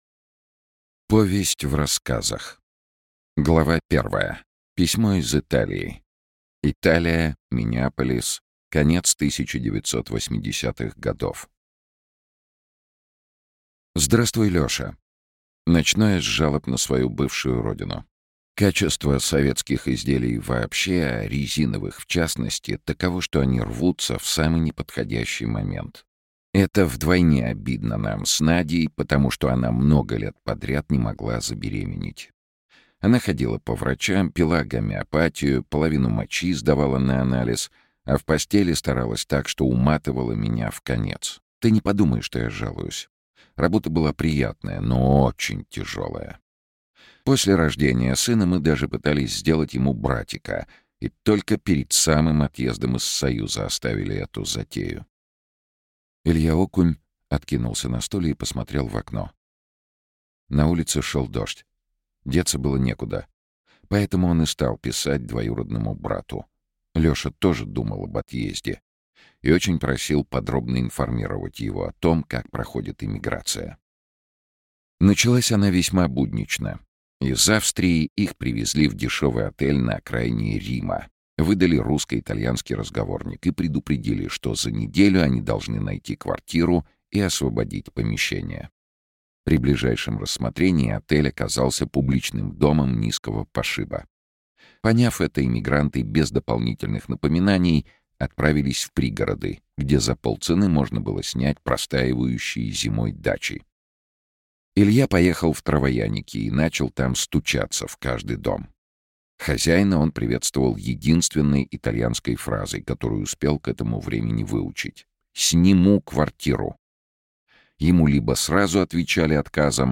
Aудиокнига Хэллоуин Автор Владимир Владмели Читает аудиокнигу Сергей Чонишвили.